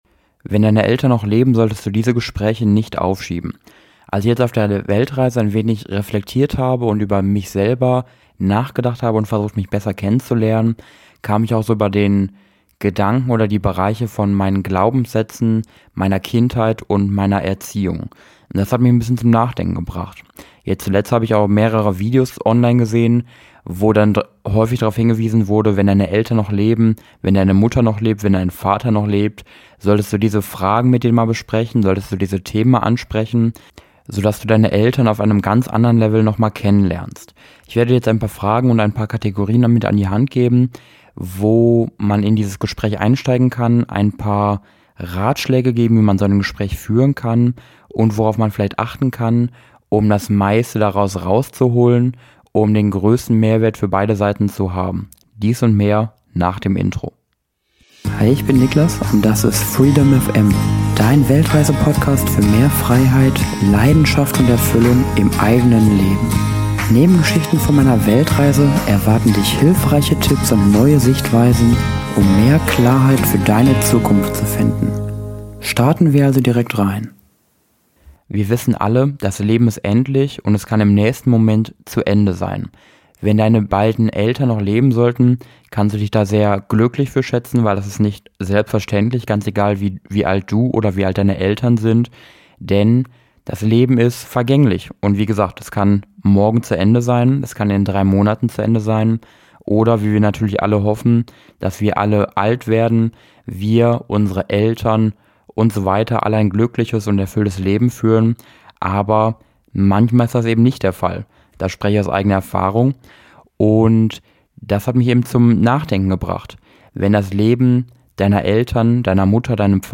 In der heutigen Solofolge möchte ich dir ein paar Fragen mit an die Hand geben, dass wenn deine Eltern (hoffentlich) noch leben, die du denen unbedingt mal stellen solltest.